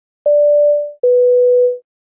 Звуки самолетов
Сигнал вызова на пассажирском самолёте